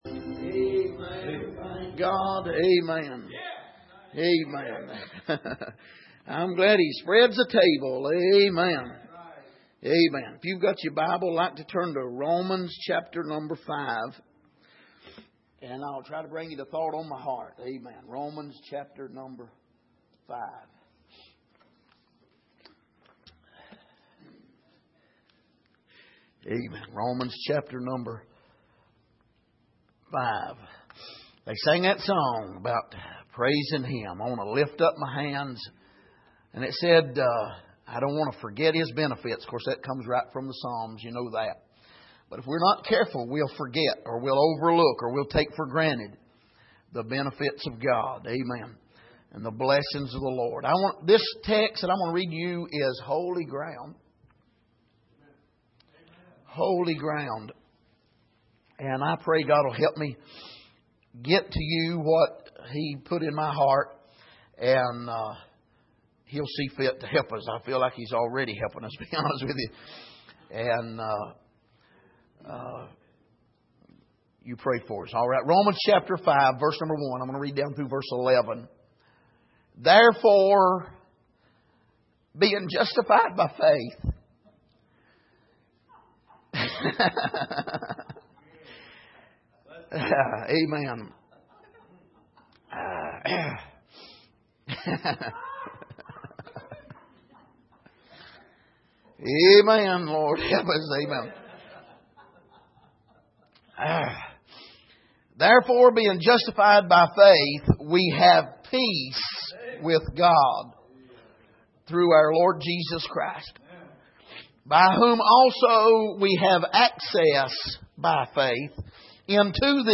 Passage: Romans 7:10 Service: Sunday Morning